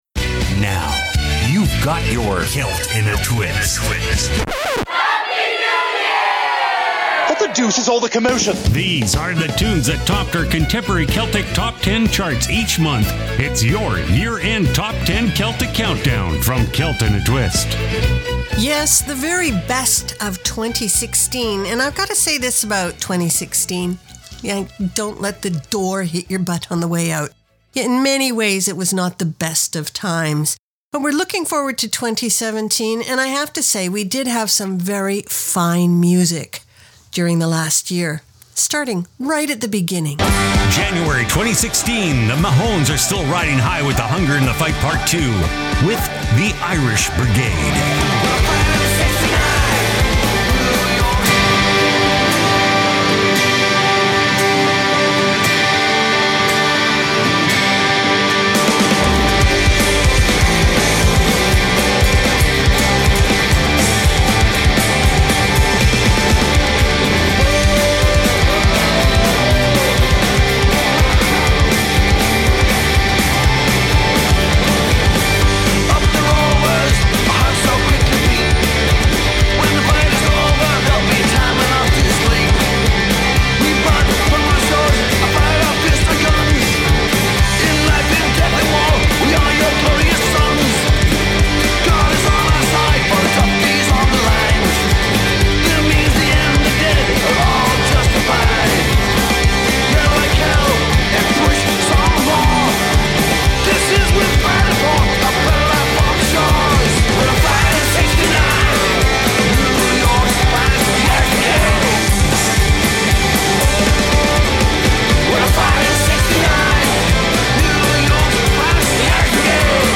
Contemporary Celtic Countdown Special for New Years